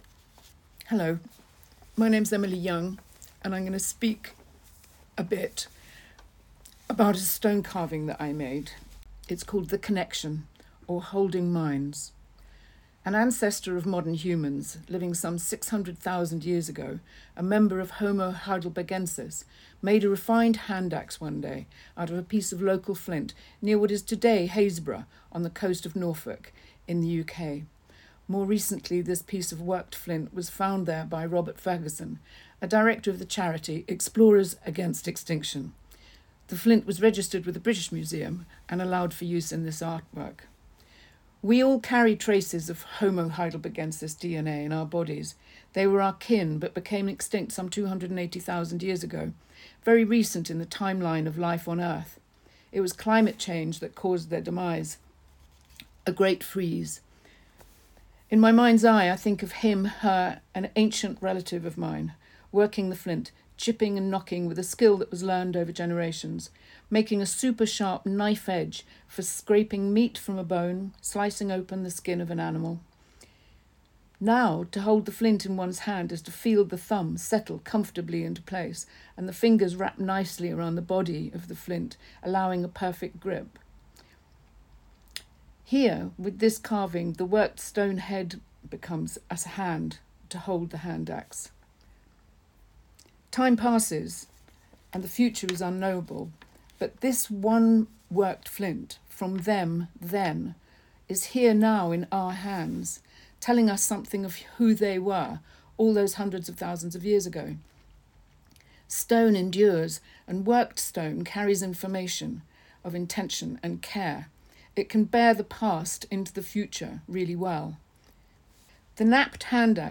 Listen to Emily Young talk about The Connection: